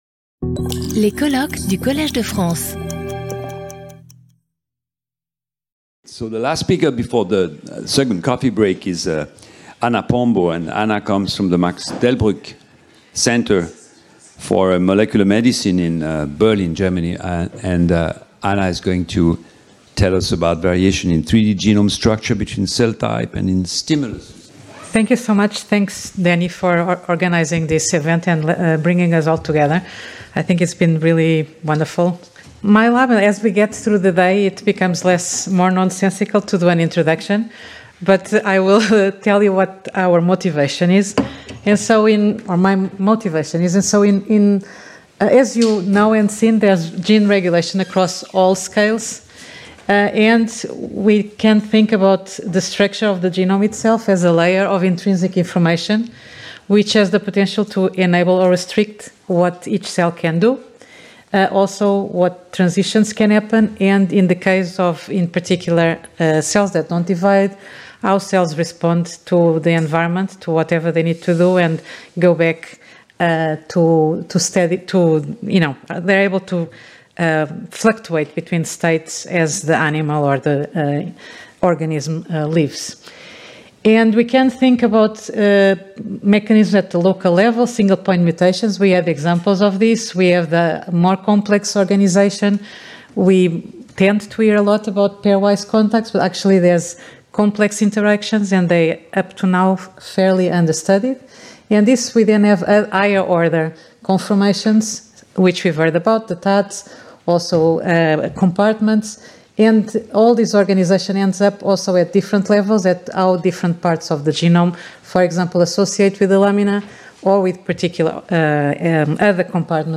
Colloque